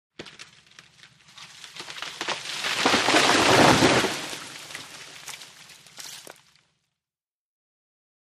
TreeFallTrunkBreak PE698701
IMPACTS & CRASHES - FOLIAGE TREE: EXT: Large tree falling, trunk break, fall & ground impact, foliage movement.